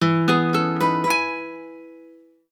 Acoustic_Guitar.ogg